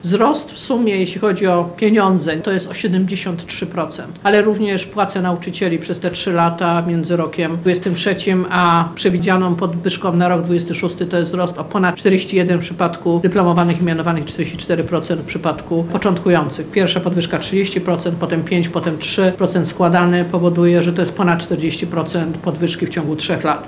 W 2026 roku wydatki na edukację wyniosą 2,6 procent PKB. Za rządów PiS to był spadek do poziomu 1,87 procent PKB - mówiła w Lublinie wiceministra edukacji